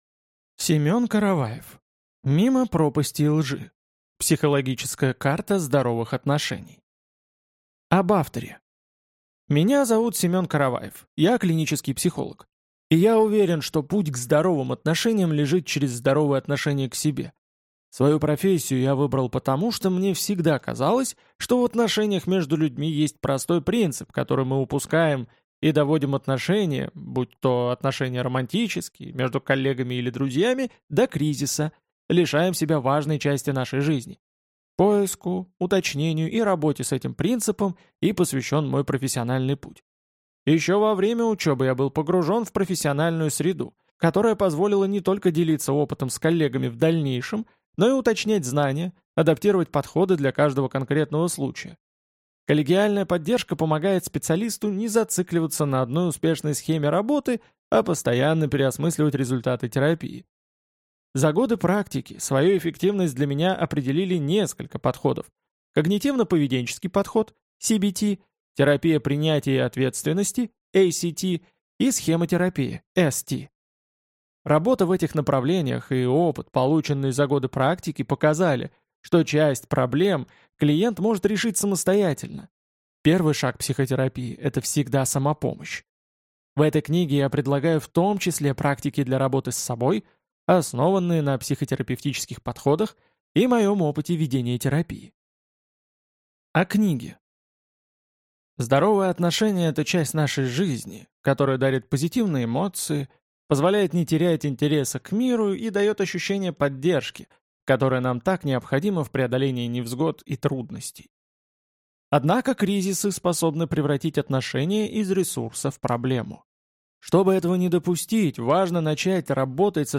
Аудиокнига Мимо пропасти и лжи. Психологическая карта здоровых отношений | Библиотека аудиокниг